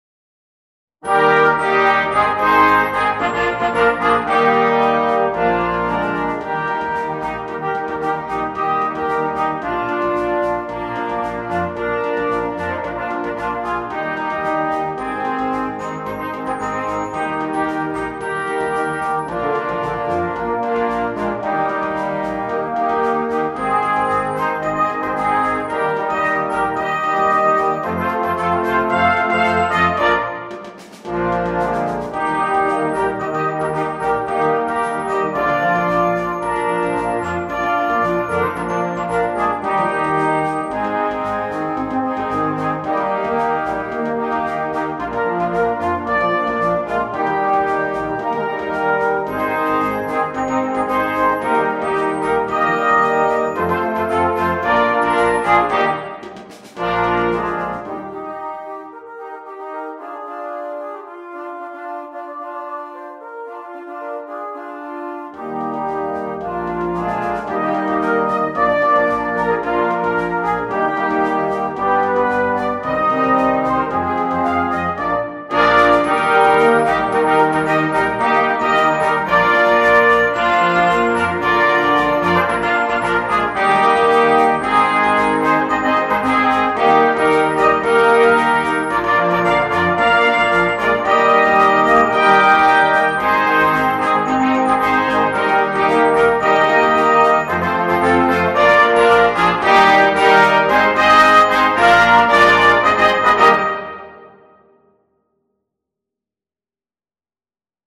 Sheet Music for Brass Band
Full Band
without solo instrument
Entertainment